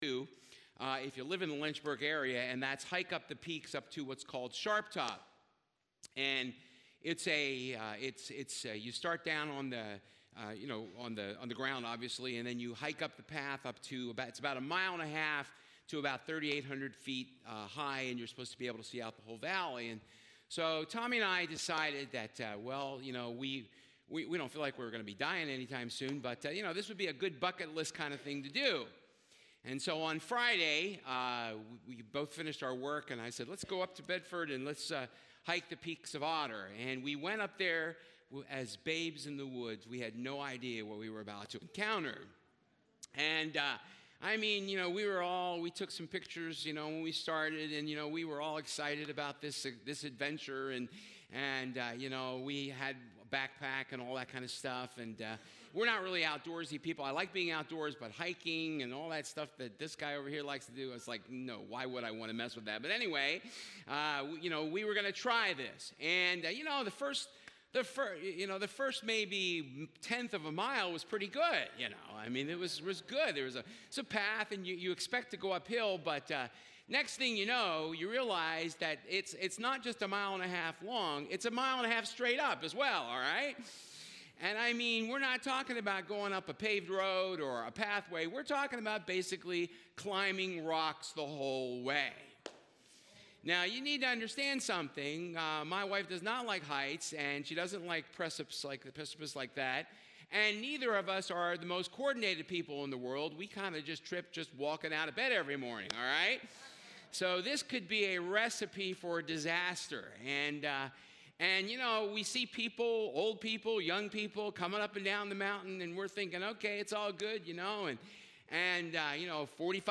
Misc Passage: Hebrews 12 Service Type: Sunday Service Perseverance